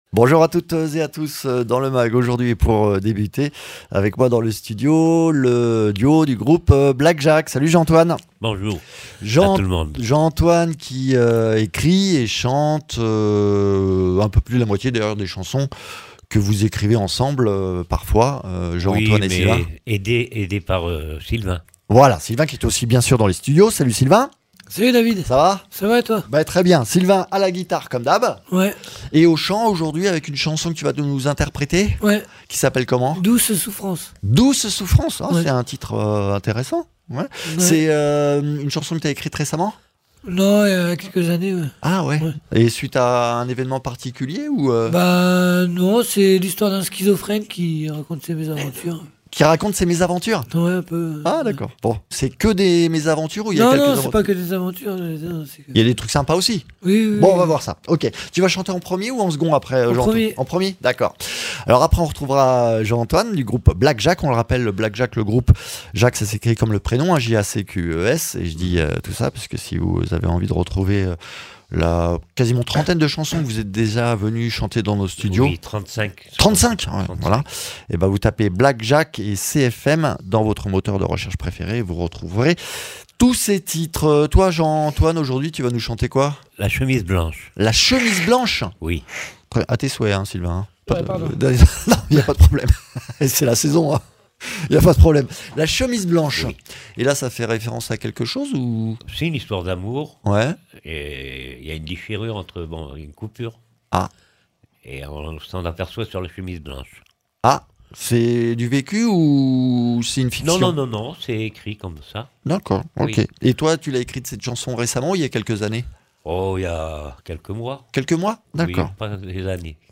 dans nos studios